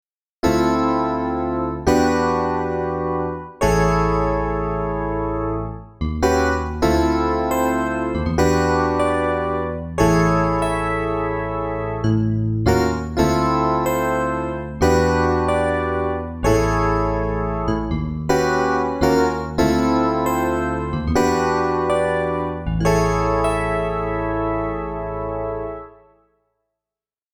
Und das hier?: Hier noch ein Electric Piano: Welcher Klangerzeuger könnte das sein?...